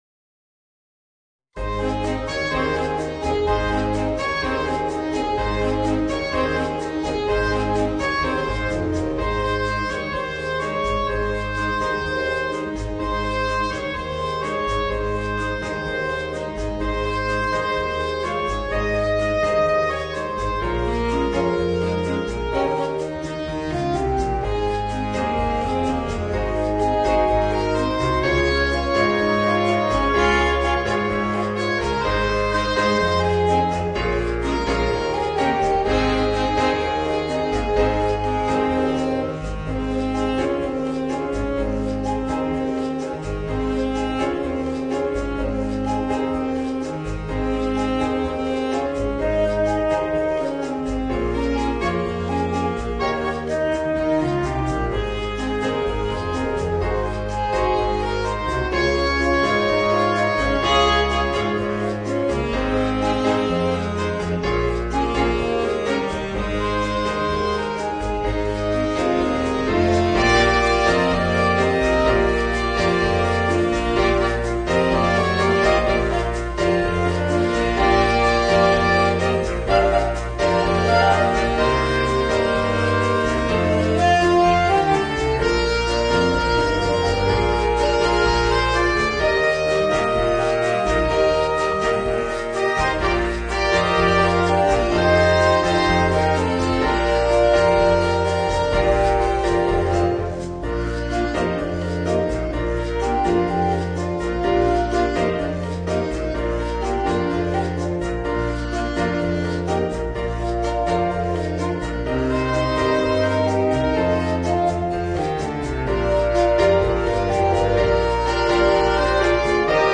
Voicing: 4 Saxophones